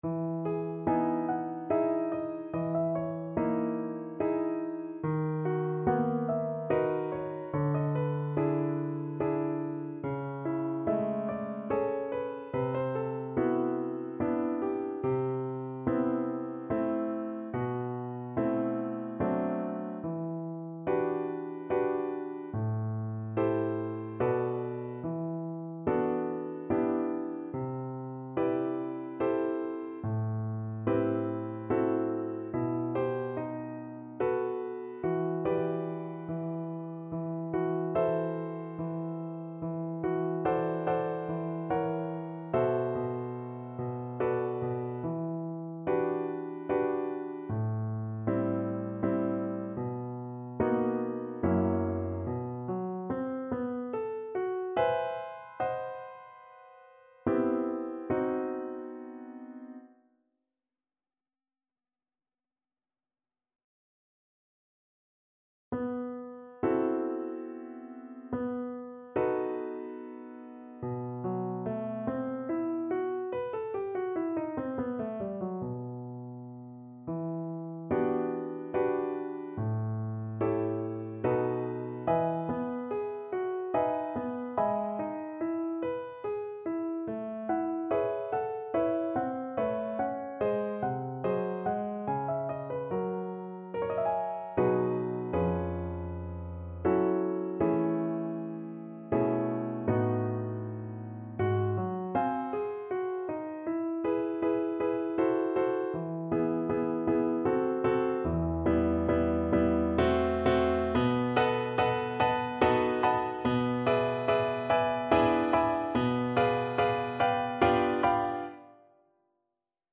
Play (or use space bar on your keyboard) Pause Music Playalong - Piano Accompaniment Playalong Band Accompaniment not yet available transpose reset tempo print settings full screen
E minor (Sounding Pitch) (View more E minor Music for Cello )
3/4 (View more 3/4 Music)
Andante =72